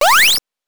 jump_1.wav